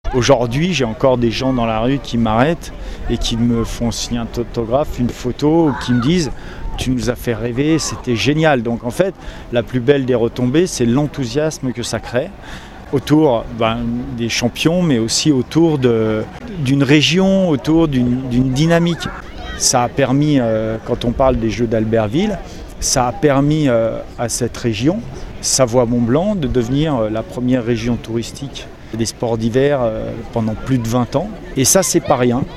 Le nouveau patron des JO 2030 nous parlait des retombées de son titre olympique il y a quelques mois, plus de 30 ans après Albertville 92 (ITW).